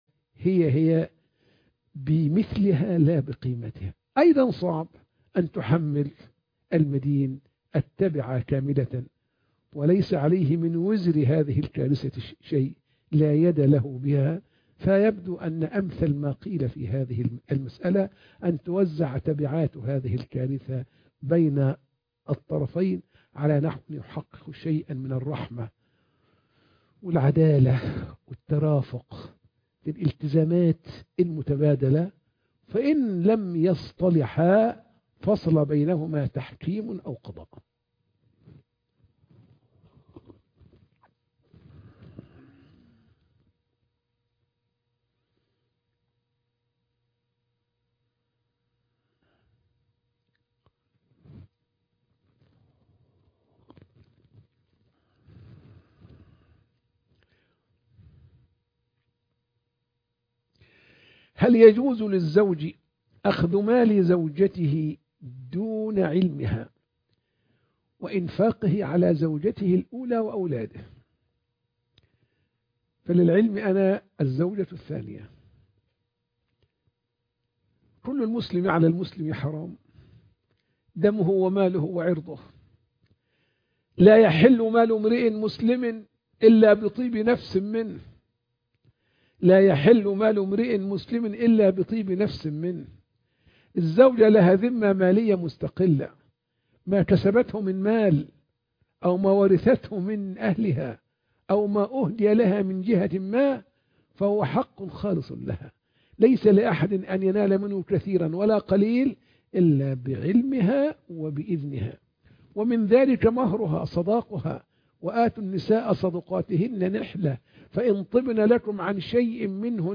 فتاوى على الهواء مباشرة (8)